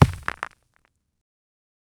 Footsound Sound Effects - Free AI Generator & Downloads
stong-impact-sound-after--3ciokyqx.wav